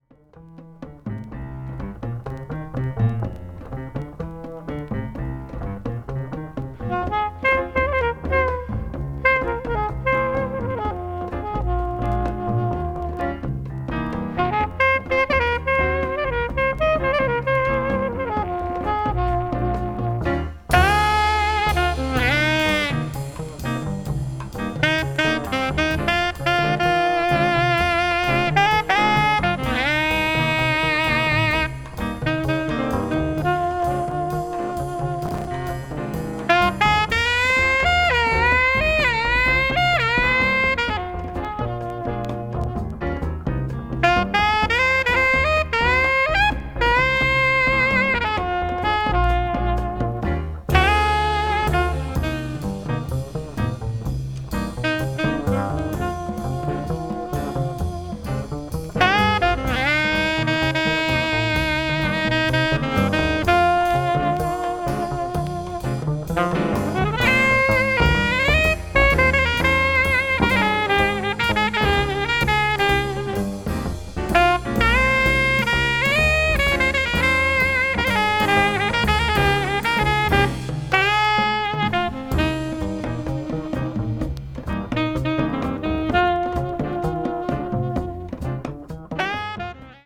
afro cuban jazz   cool jazz   modern jazz   post bop